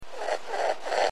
Die Farmerama Tierstimmen
Hase.wav.mp3